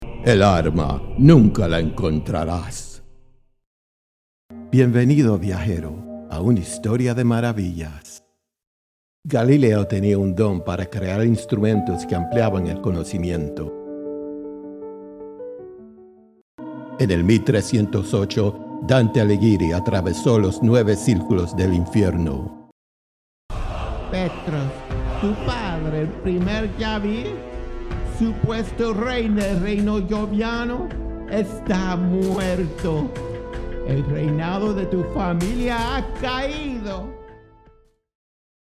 Spanish Character (.39)
A spirited mix of character voices en Español—ideal for dubbing, animation, and immersive storytelling.
All content recorded in Studio 23, Nashville, Tennessee.
Spanish-Character-Reel.mp3